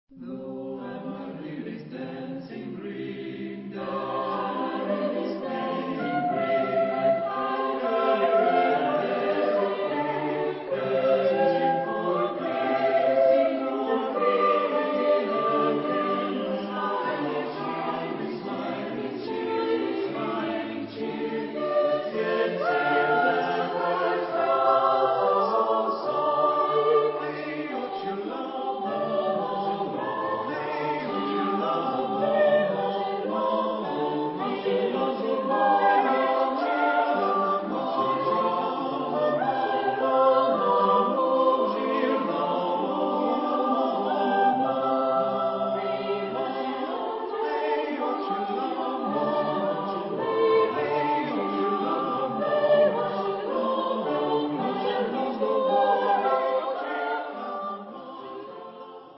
Genre-Style-Form: Madrigal ; Secular ; Renaissance
Type of Choir: SAATB  (5 mixed voices )